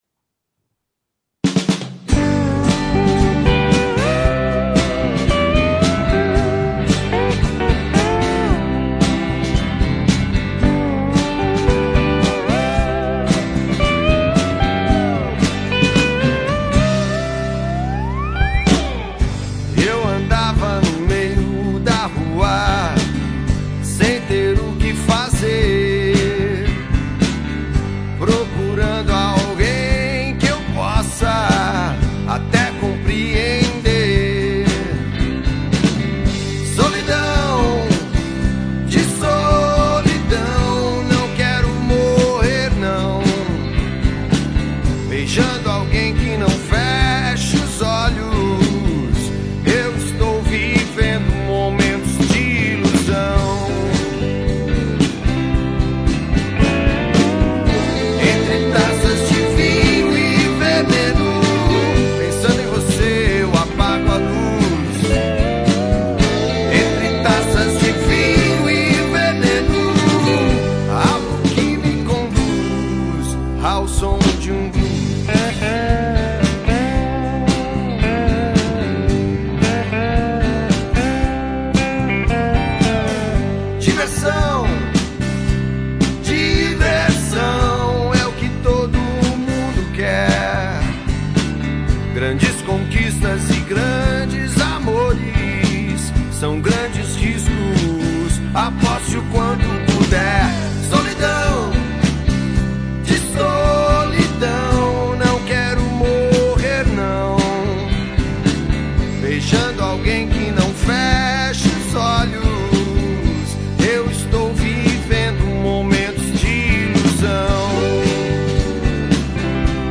EstiloBlues